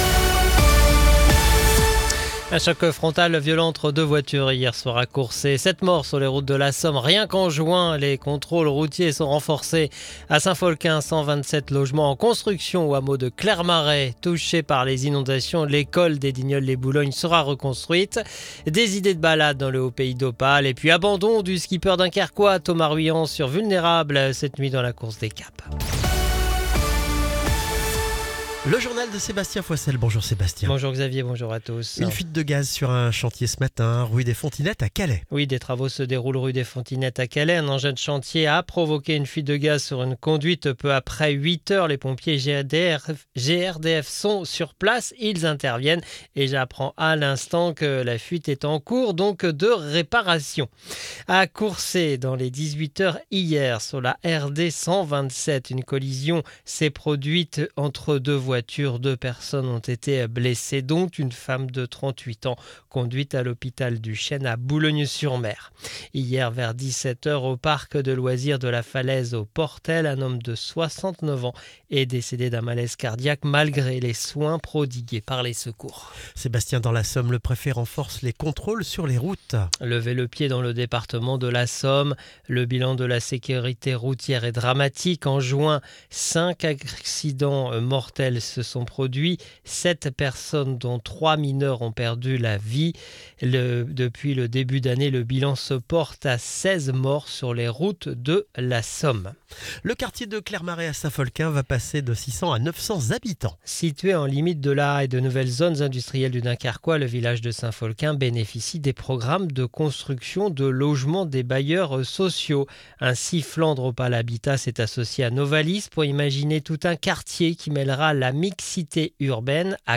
Le journal du vendredi 4 juillet 2025